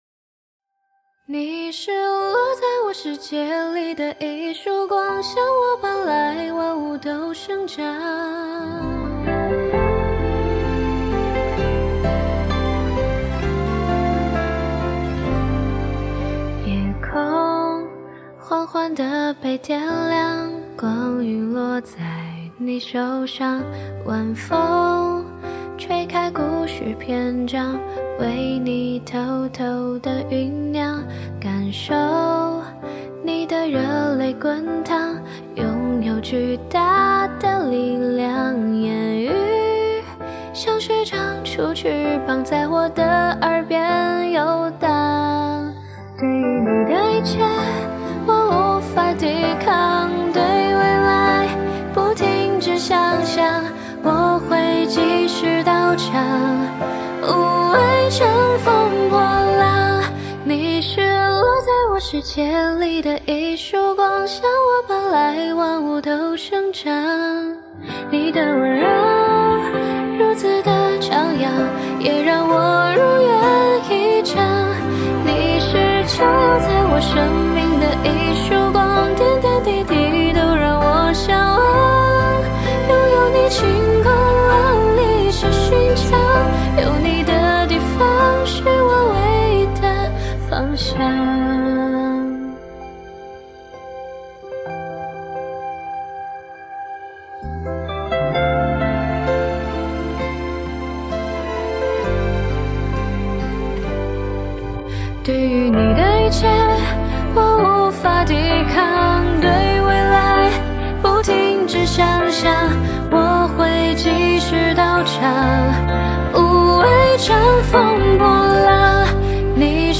无鼓伴奏